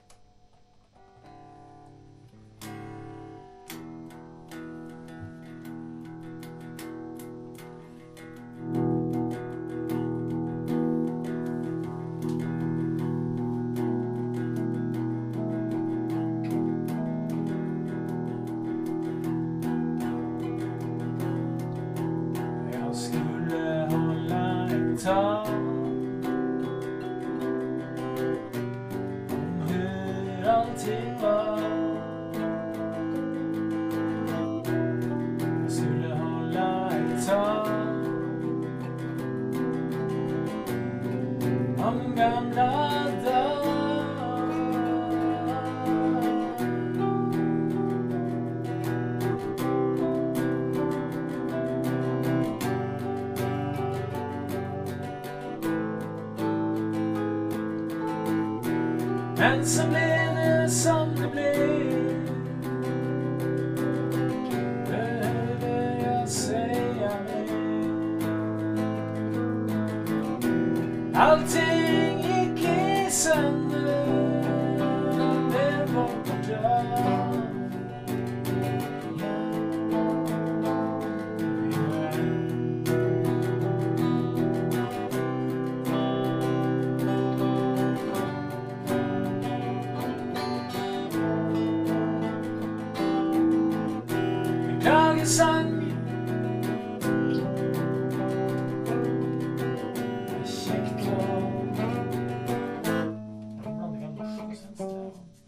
Det blev bara två stycken jam-sessions 2013, men som tur är var vi kreativa bägge gångerna, så vi fick ihop material till en väldigt bra OBSsessions-skiva, och kanske också till en jul-skiva.